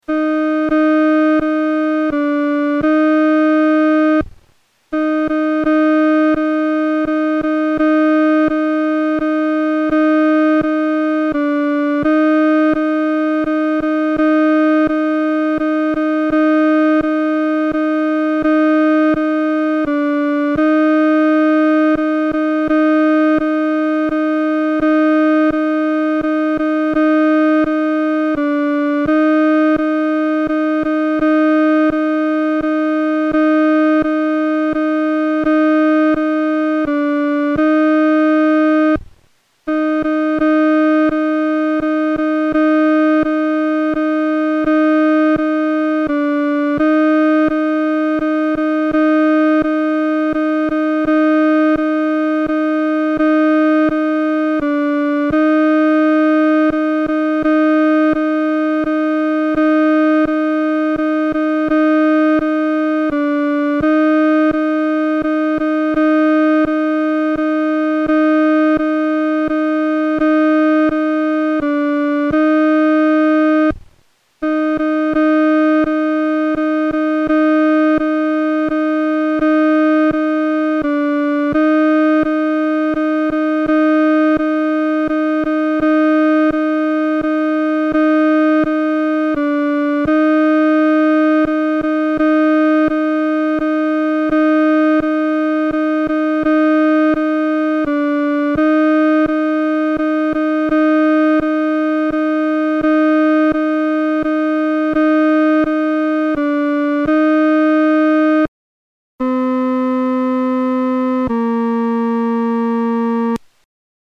伴奏（女低）